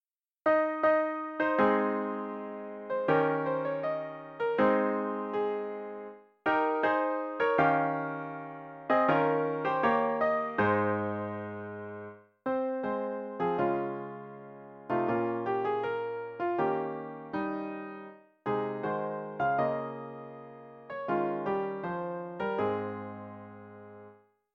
076.a-Röddin (Ég stóð um nótt) (solo+piano)